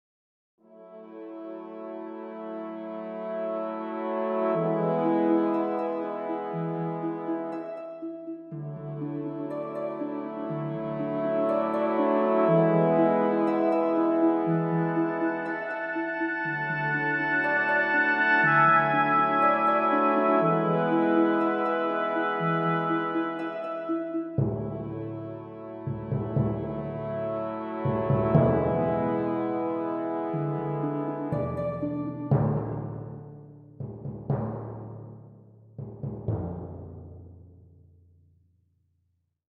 Here's are some sound track clips created with GarageBand 2.0 and "Jam Pack 4 -- Symphony Orchestra"
SoundTrack-Intro.mp3